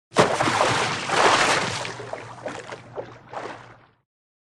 Звуки якоря
Шум якоря, погружающегося в воду